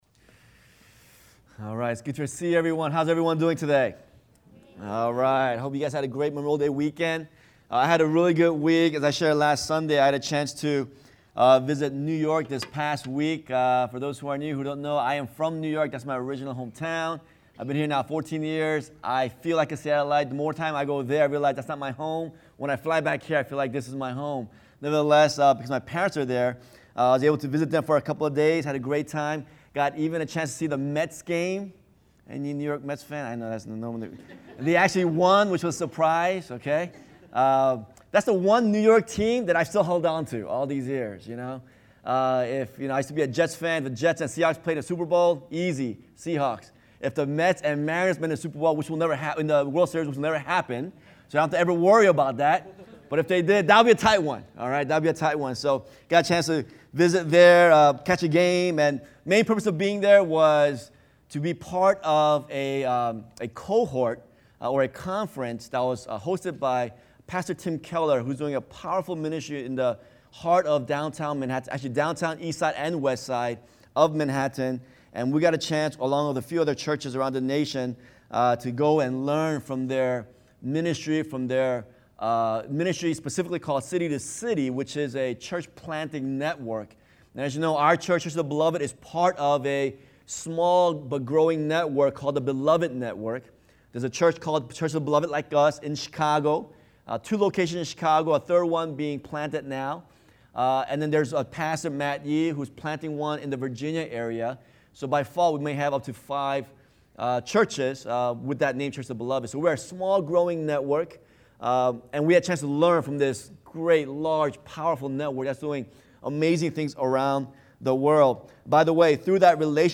sermons - Church of the Beloved